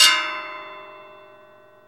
METAL HIT 2.wav